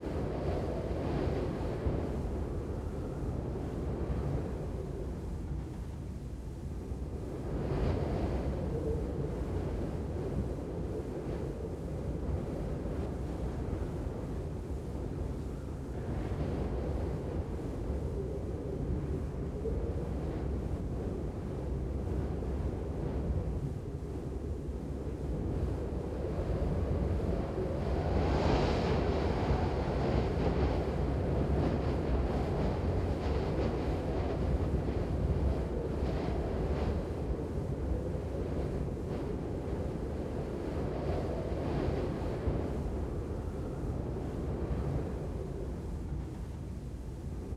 Desert_Wind__Whistle.L.wav